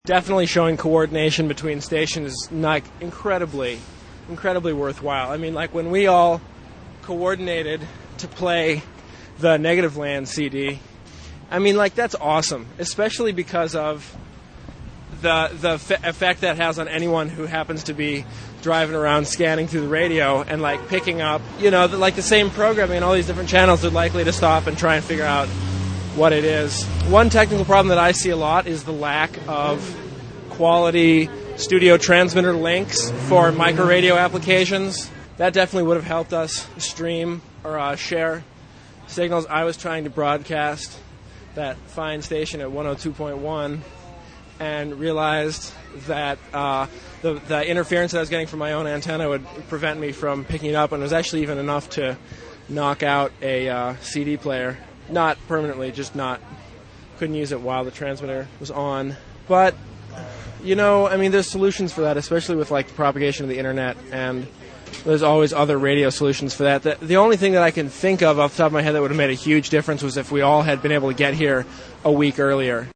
We'll begin with cuts from interviews done with a handful of Mosquito Fleet participants; it was hard to catch people flitting back and forth between transmitters, and some people don't want any publicity.